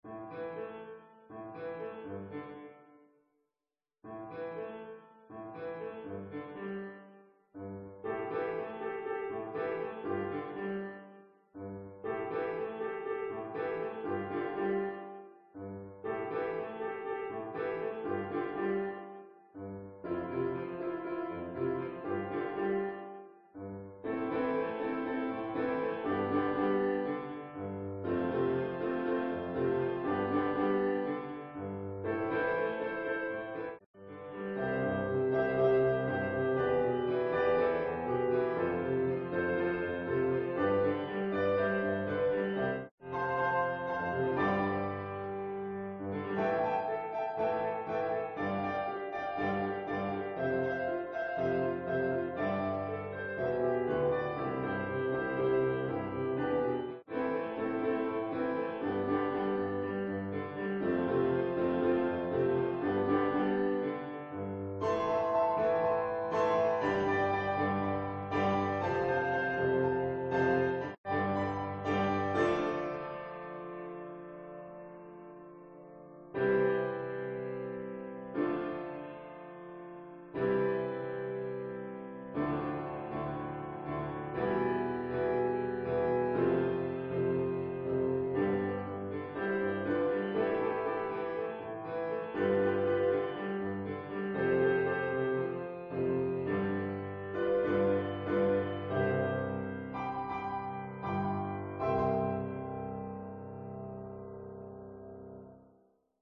accomp sample